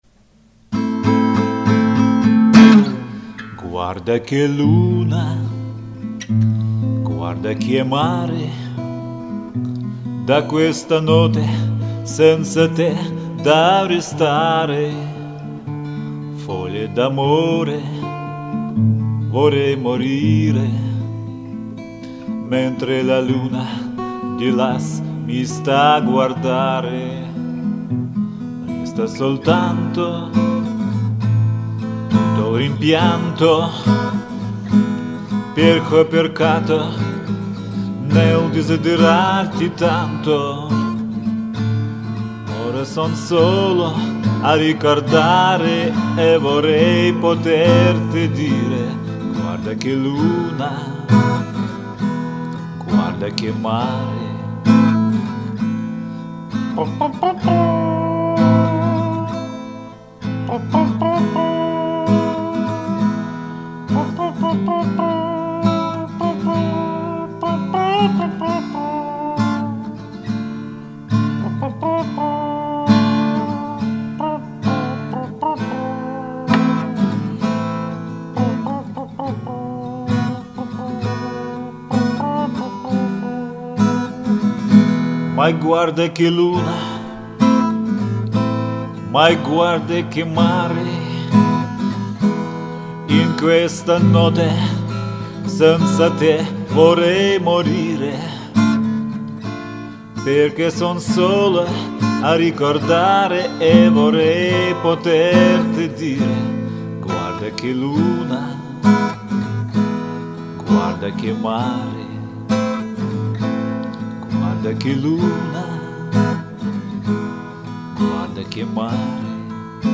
Армейские и дворовые песни под гитару